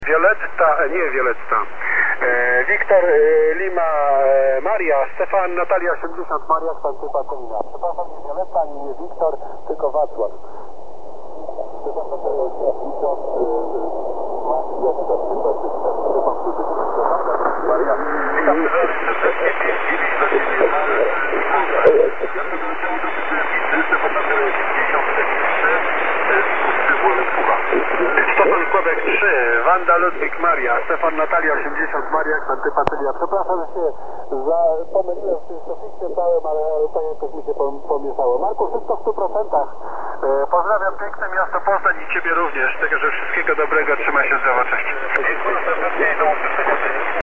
Rysunek zawiera schemat filtra a pliki dźwiękowe pokazują efekty jego działania przy odbiorze fonii i ssb.
działanie w trybie ssb
filtr_fonia.mp3